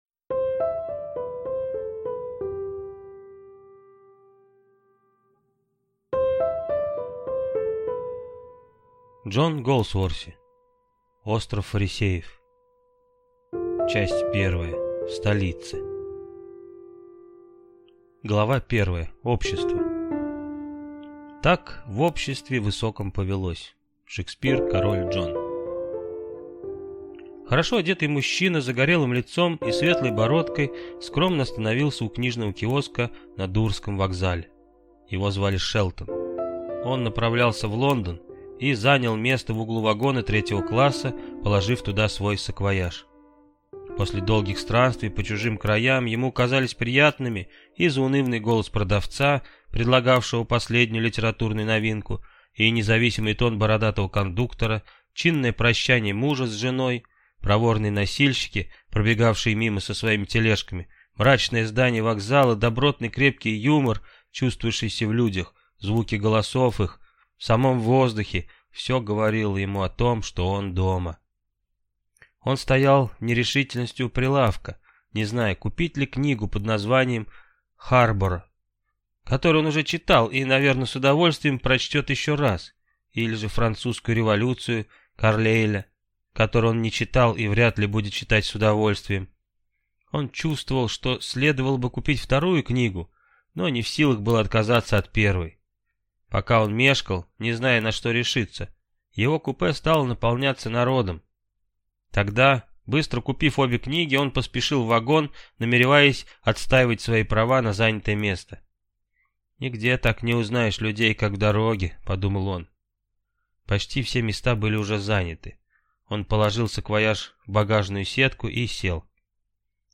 Аудиокнига Остров фарисеев | Библиотека аудиокниг